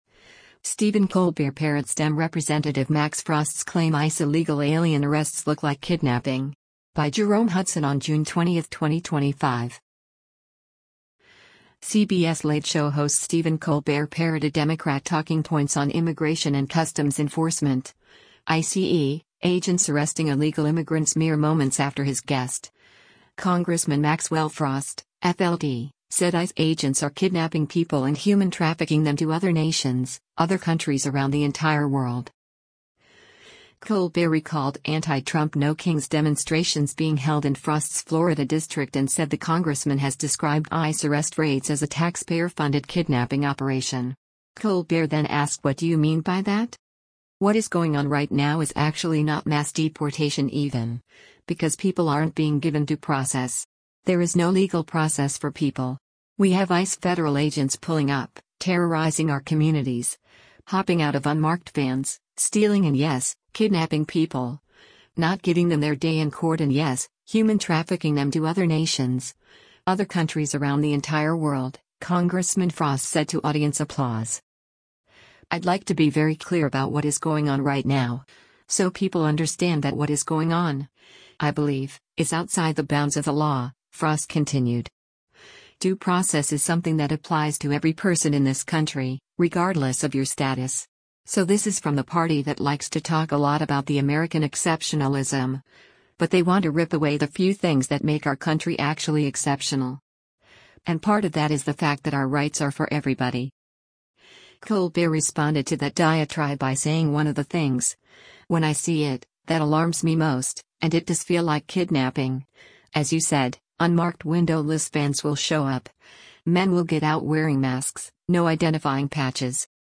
NEW YORK - OCTOBER 10: The Late Show with Stephen Colbert and guest Rep. Maxwell Frost dur
“What is going on right now is actually not mass deportation even, because people aren’t being given due process. There is no legal process for people,. We have ICE federal agents pulling up, terrorizing our communities, hopping out of unmarked vans, stealing and yes, kidnapping people, not giving them their day in court and yes, human trafficking them to other nations, other countries around the entire world,” Congressman Frost said to audience applause.